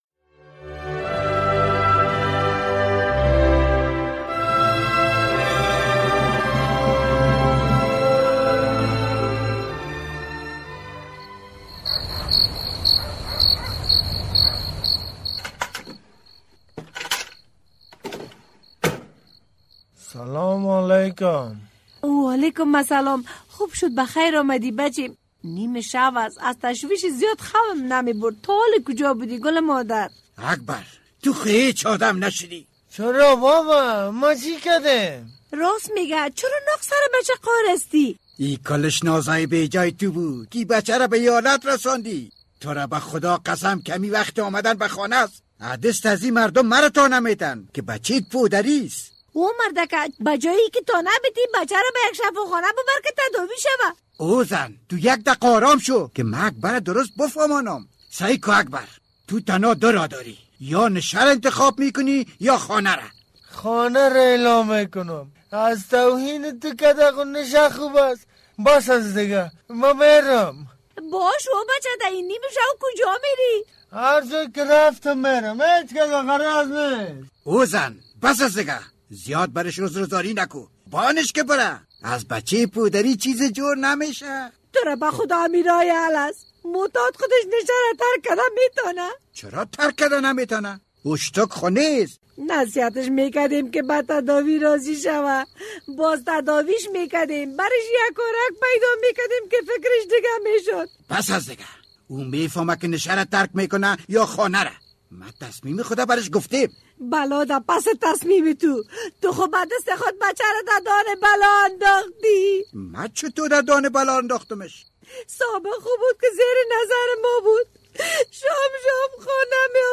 درامه: چرا اکبر تا نصف شب به خانه نامده و بعداً چه اتفاق می افتد؟